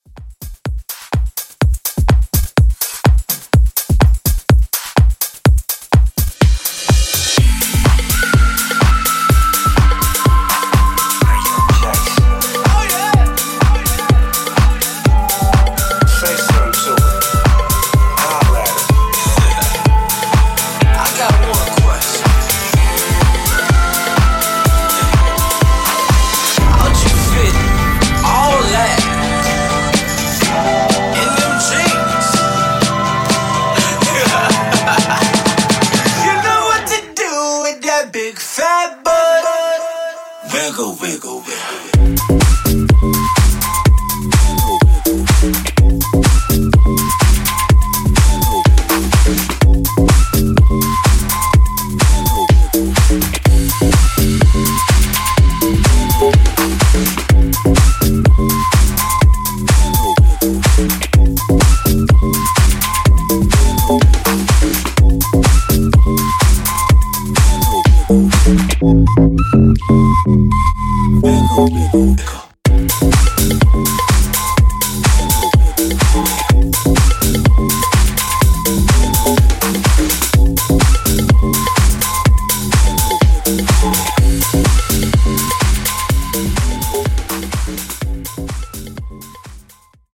Deep House Repeat)Date Added